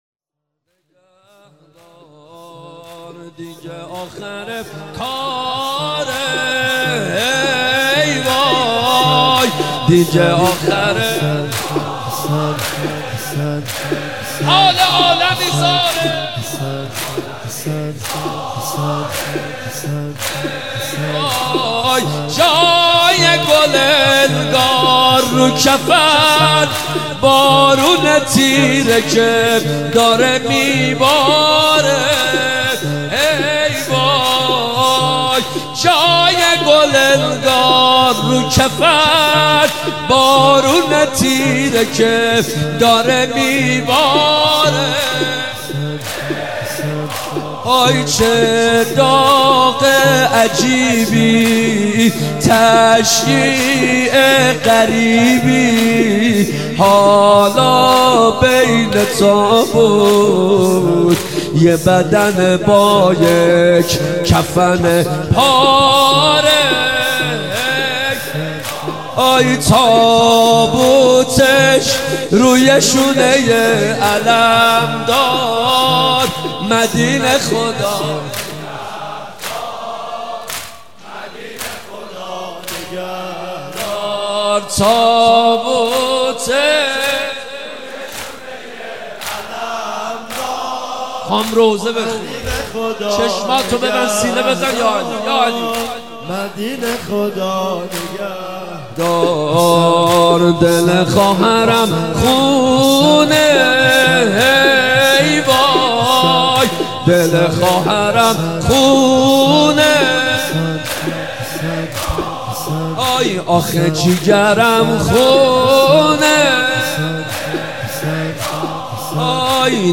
مناسبت : شهادت امام حسن مجتبی علیه‌السلام
قالب : شور